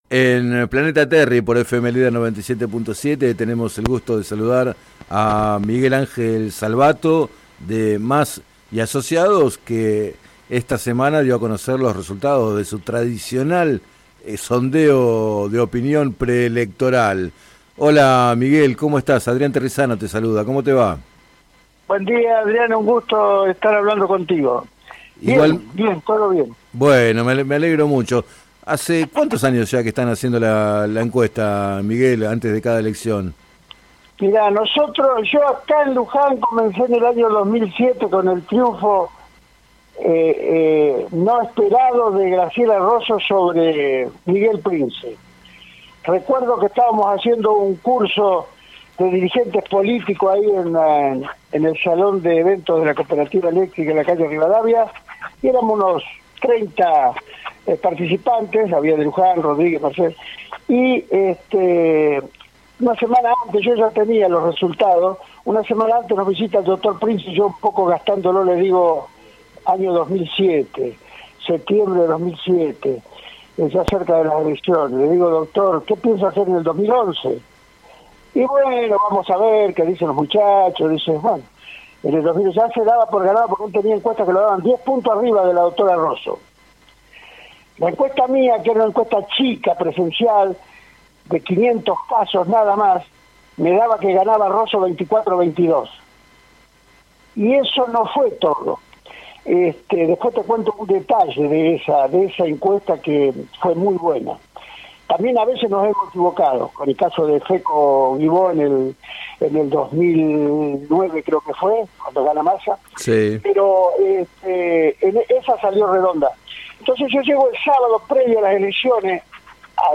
Entrevistado en el programa Planeta Terri de FM Líder 97.7